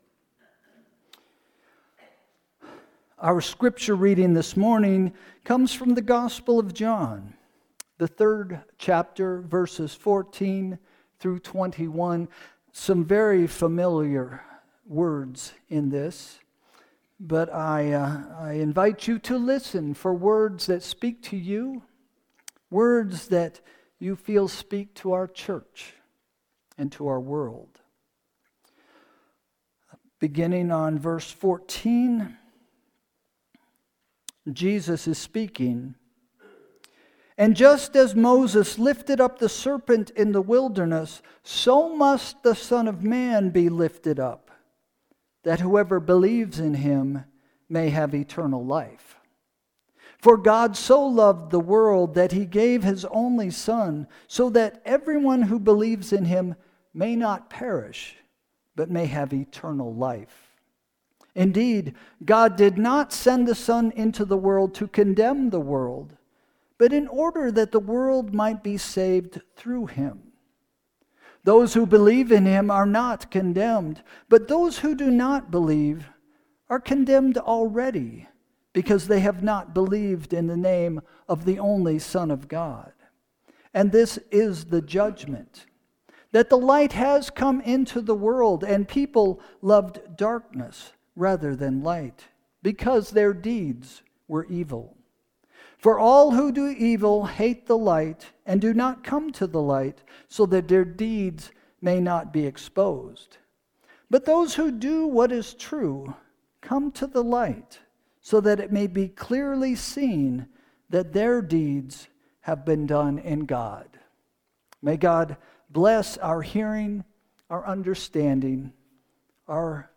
Sermon – June 8, 2025 – “Joy is a Choice”